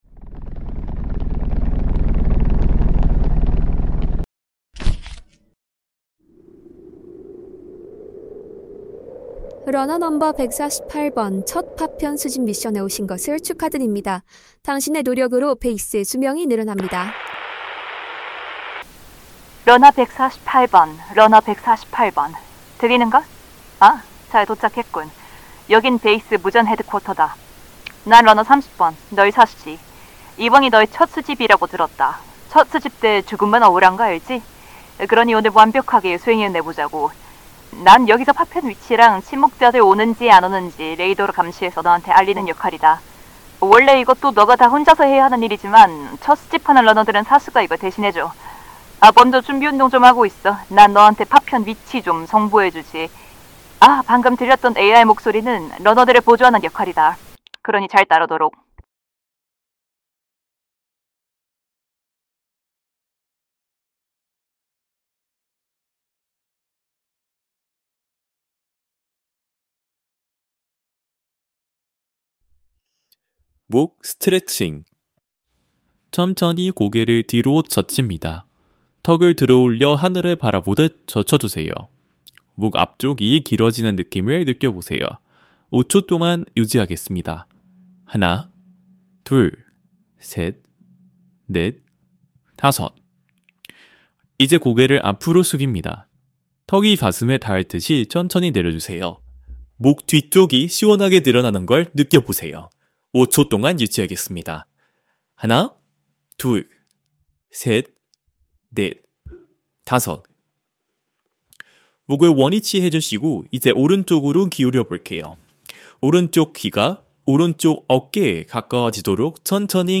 스토리텔링 유산소_7분 샘플.mp3
현재 스토리텔링형 운동과 챌린지 프로그램을 통해 보다 즐겁고 지속 가능한 운동 경험을 제공하는 서비스를 개발 중입니다. 참고하실 수 있도록 스토리텔링형 운동 프로그램의 샘플 음성본(약 7분 분량)을 함께 첨부했습니다.